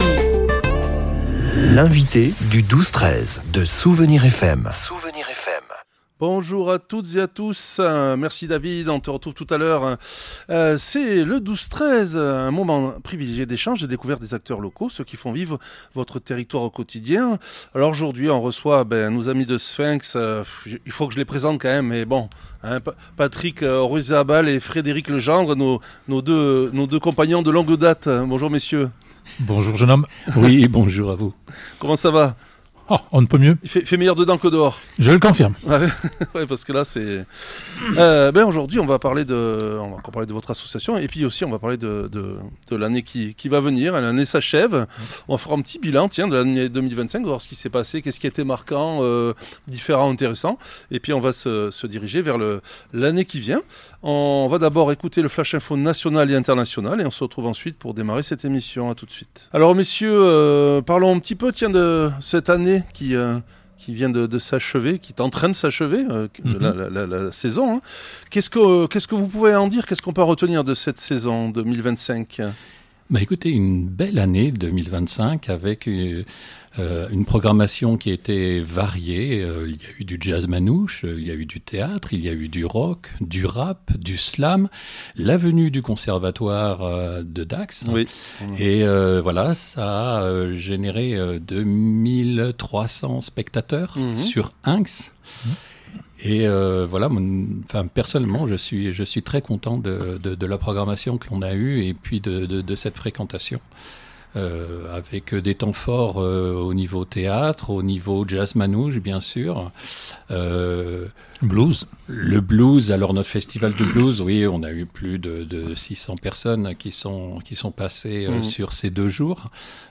Avant les fêtes, nos amis de Hinx sont passés en studio !